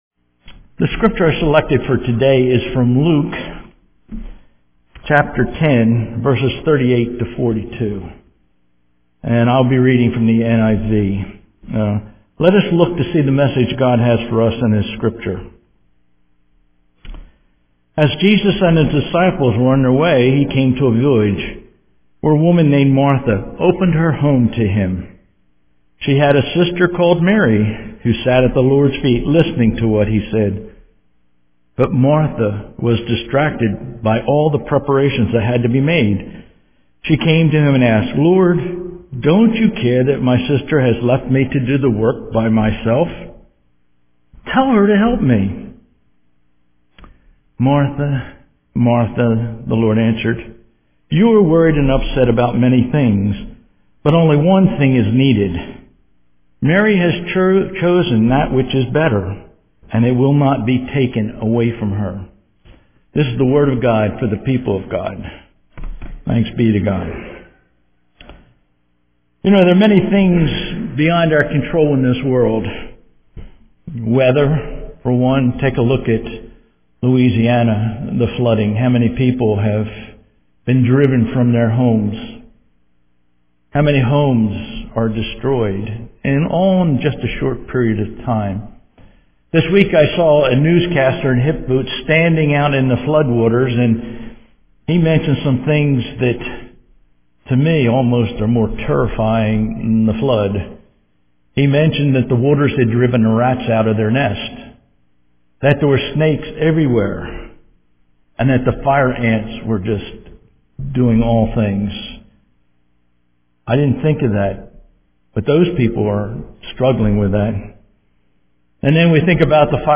The following recording is from the 11:00 service.